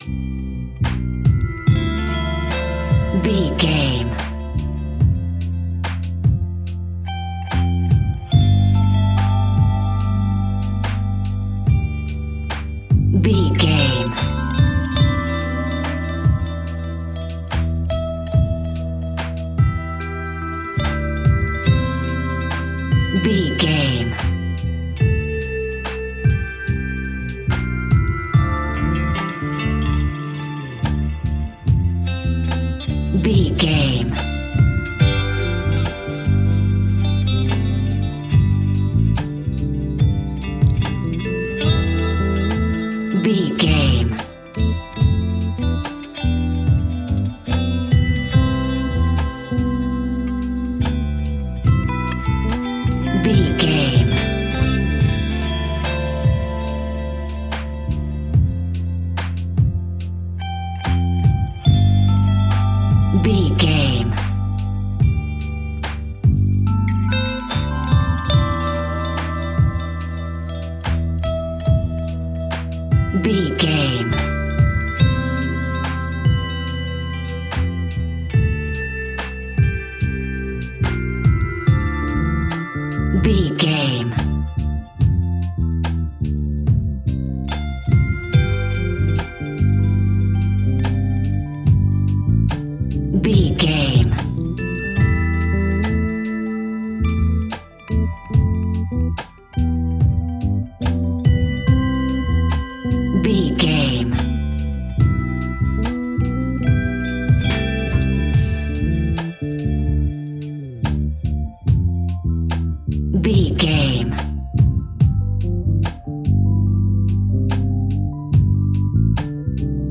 Ionian/Major
chilled
laid back
Lounge
sparse
new age
chilled electronica
ambient
atmospheric